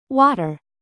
water.mp3